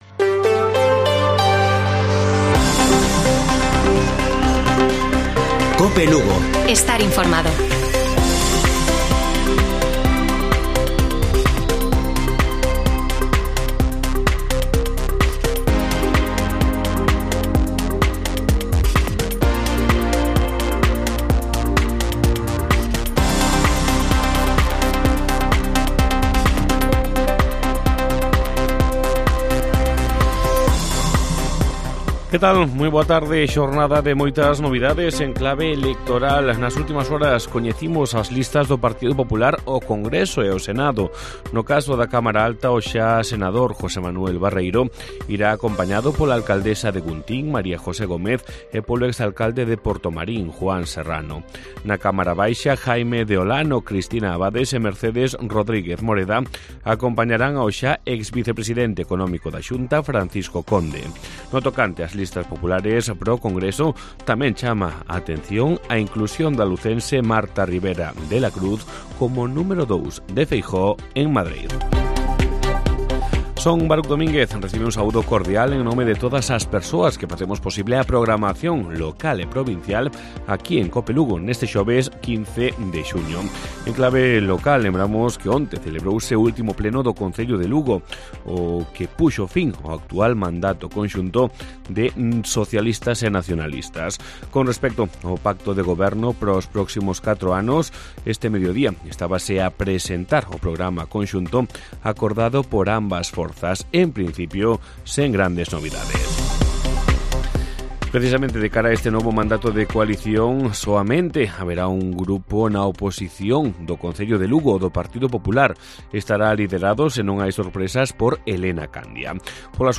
Informativo Mediodía de Cope Lugo. 15 DE JUNIO. 13:50 horas